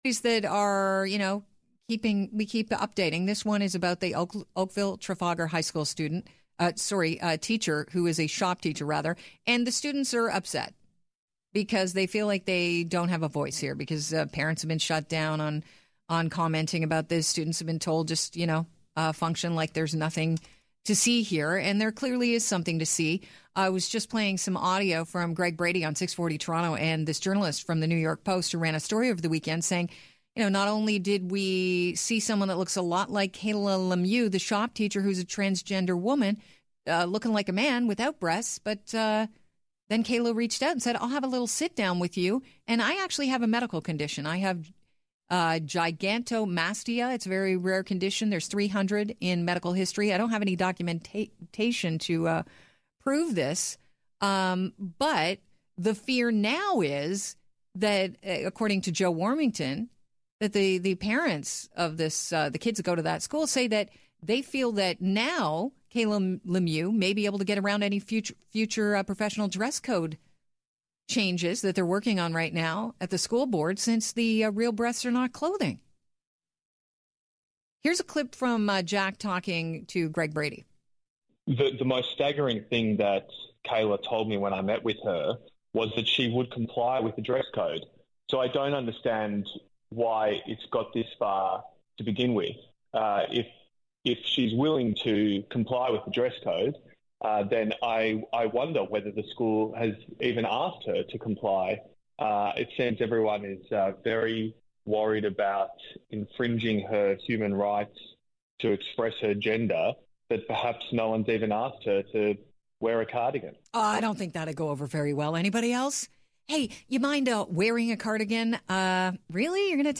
She has been published by Global News, the National Post and the Financial Post, and has spoken on employment issues as a guest on several radio shows, online employment law Q&As and podcasts.